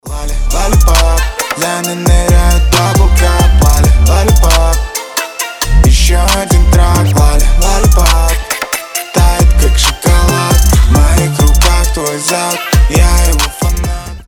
мужской голос
Нарезка заводной песни